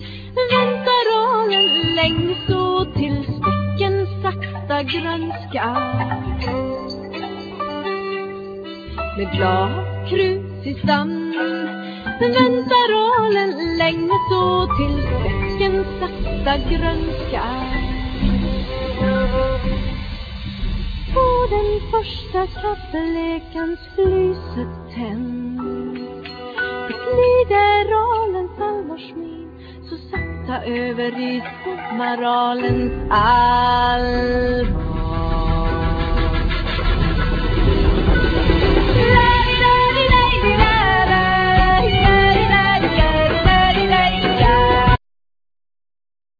Vocals
Fiddle
Saxophone,Flute
Percussion
Bass
Piano,Keyboards